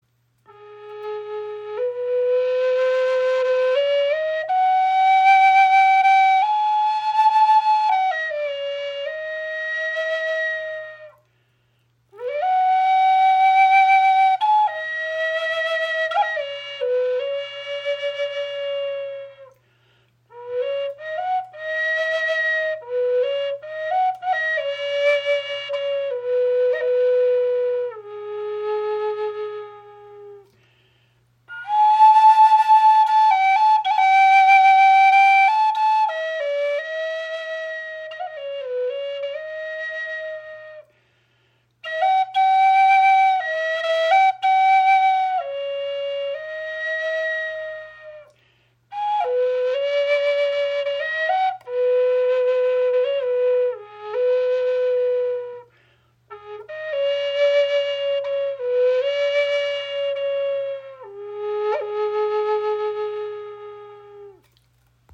EarthTone Spirit Flöten | Handgefertigt | A in 432 Hz
Unsere EarthTone Spirit Flöte in A-Moll (432 Hz) ist handgefertigt aus einem Stück spanischer Zeder. Das weiche Holz verleiht ihr eine warme, resonante Klangstimme.